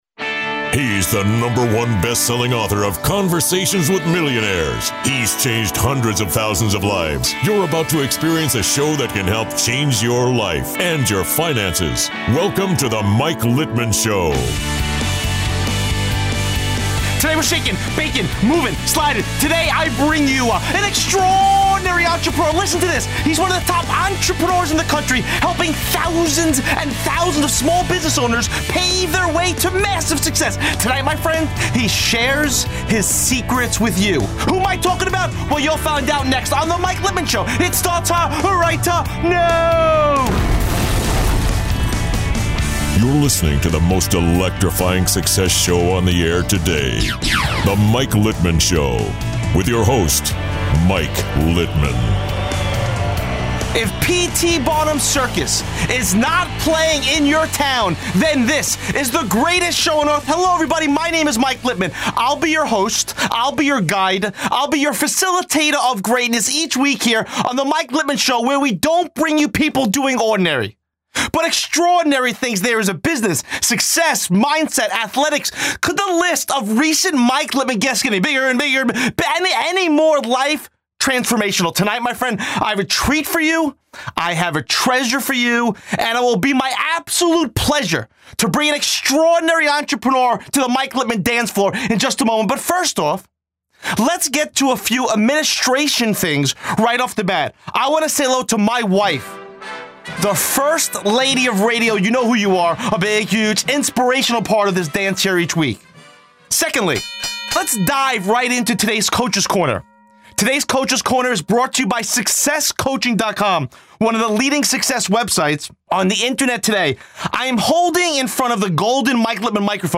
It’s hard to when someone comes at you with so much energy! In this interview I went into some serious mindset, success, and action principals from my own life - things that have directly contributed to me getting my training business off the ground, overcoming limiting beliefs, and doing ONE THING that separated me from all my competitors.